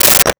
Telephone Receiver Put Down 02
Telephone Receiver Put Down 02.wav